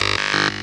sci-fi_code_fail_10.wav